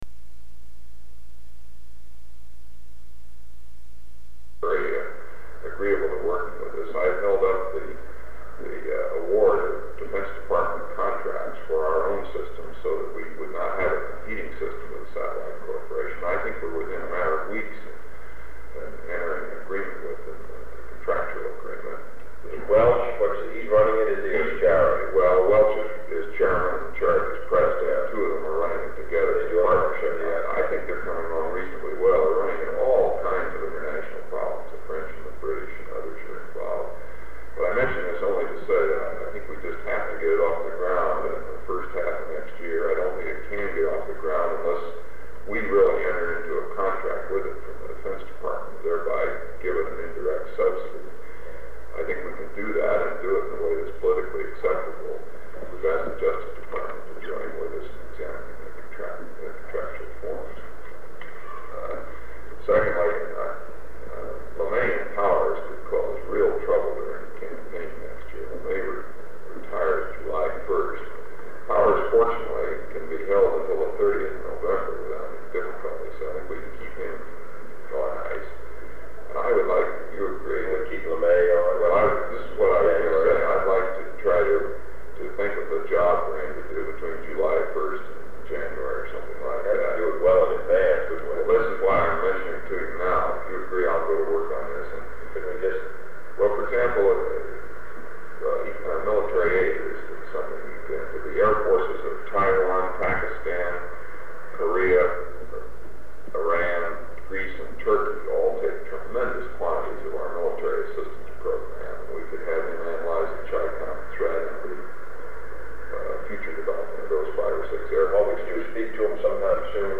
Sound recording of a meeting on defense contracts held on November 8, 1963, between President John F. Kennedy and Secretary of Defense Robert S. McNamara.
This brief discussion touches on a variety of subjects including the TFX aircraft contract issue, the futures of General Thomas Power and General Curtis LeMay, the 1964 presidential campaign, the FY1965 (Fiscal Year 1965) Budget review and stockpile discussion with Congress, and Admiral George W. Anderson’s conduct during the Cuban Missile Crisis. This sound recording has been excerpted from Tape 120/A56, which contains additional sound recording(s) preceding and following this one.